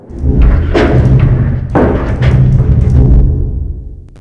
TanksFilled.wav